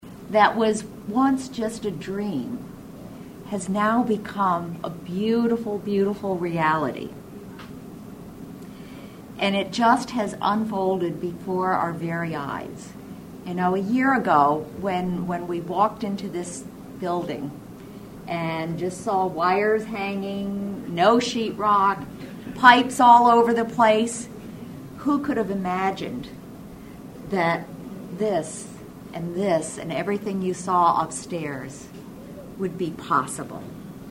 Dignitaries were among a large crowd that gathered to celebrate the opening of the Salina Health Education Center, the new home of the University of Kansas School of Medicine-Salina and the KU School of Nursing-Salina.